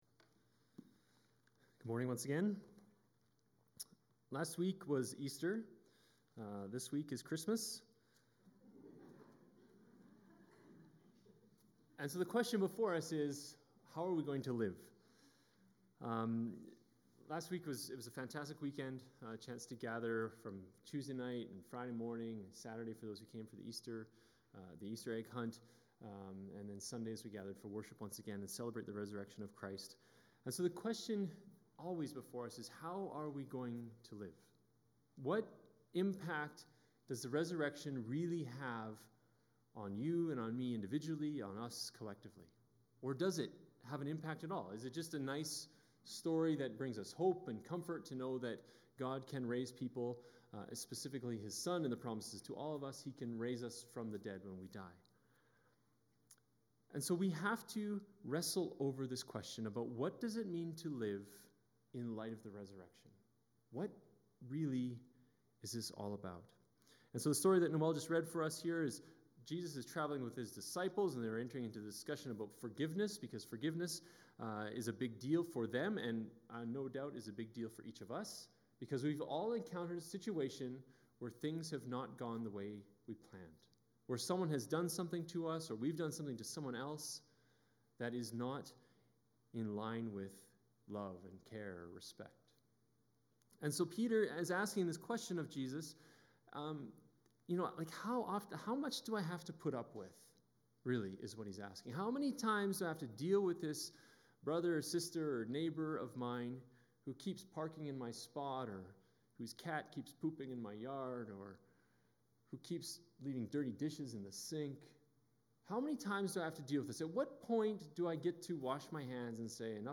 Archived Sermons | Crescent Heights Baptist Church